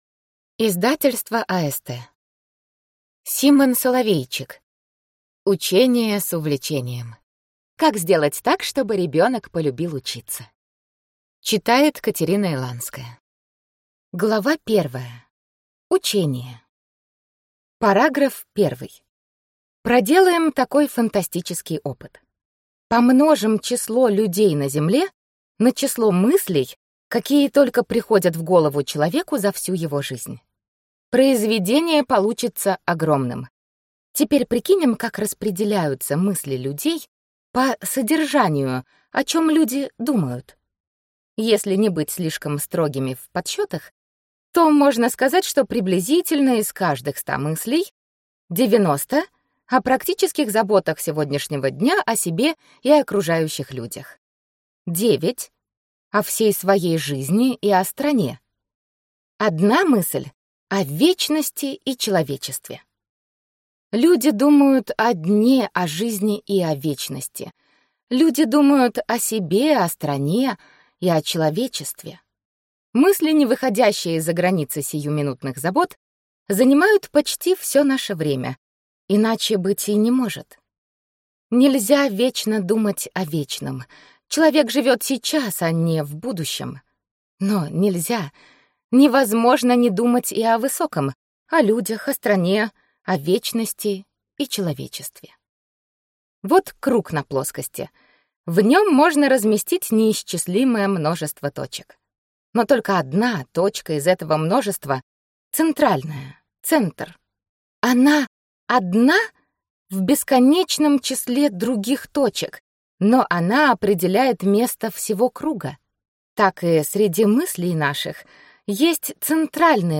Аудиокнига Учение с увлечением. Как сделать так, чтобы ребенок полюбил учиться | Библиотека аудиокниг